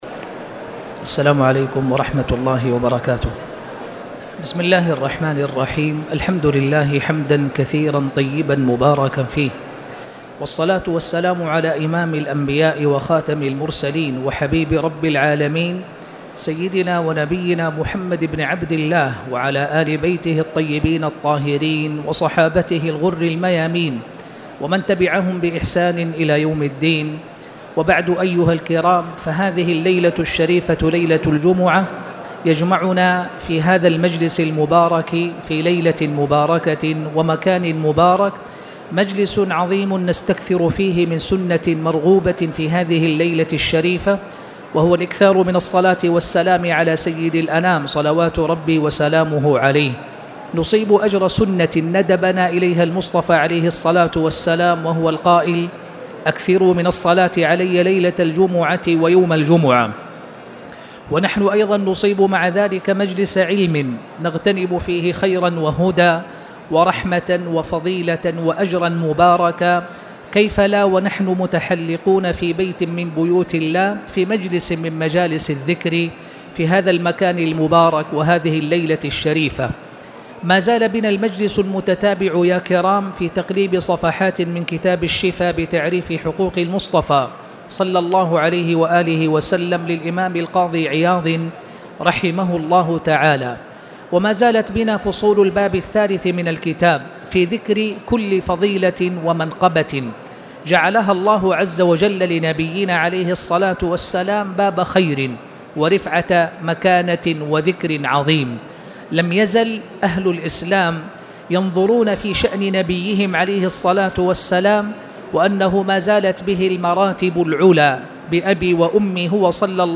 تاريخ النشر ٩ رجب ١٤٣٨ هـ المكان: المسجد الحرام الشيخ